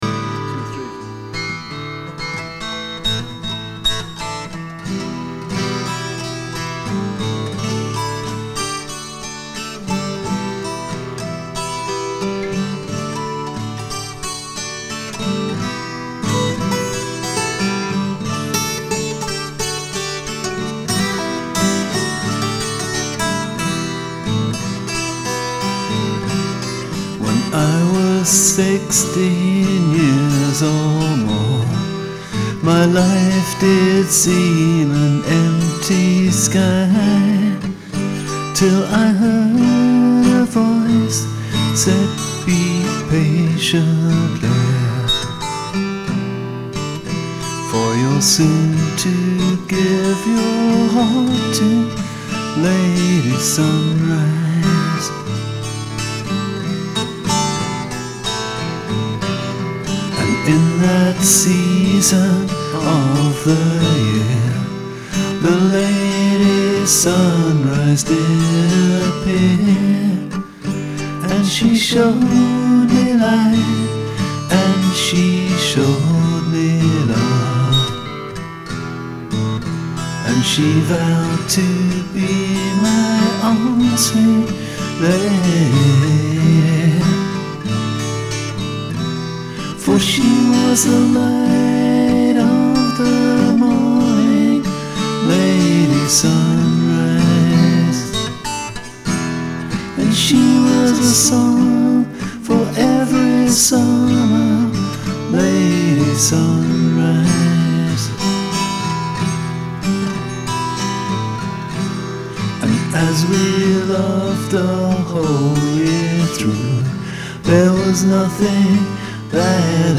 Fun with Tascam 244
The lead vocal was rather hot and the output clipped the Terratec Phase II digitiser I used to transfer the tracks a few times even on minimum input level so I need to do this again properly. Just a quick mix with no eq but some reverb (maybe too much) added.
Great 12-string sound!
Very ambient, spacious and raw.
The top-end sounds really detailed.
Man I love the sound of that 12 string..... really nice